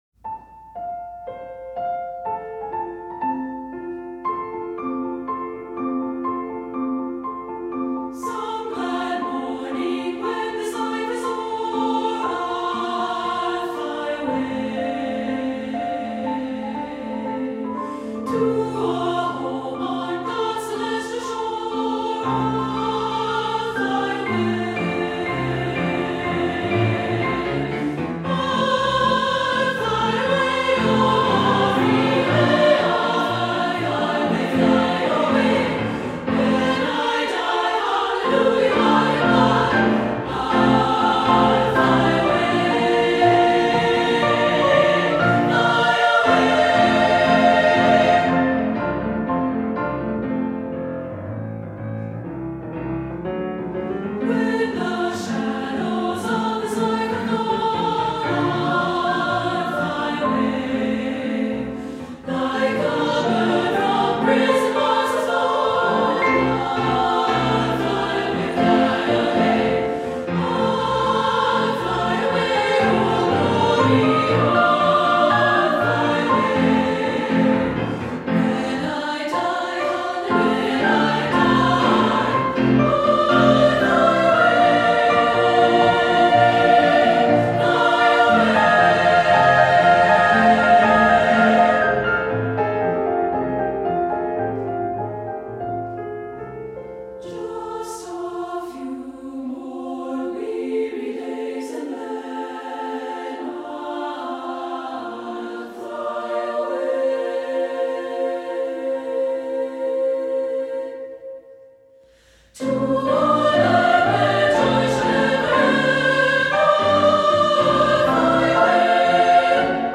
Voicing: SSAA and Piano 4 Hands